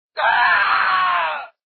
Screaming Boba Fett Sound Effect Free Download
Screaming Boba Fett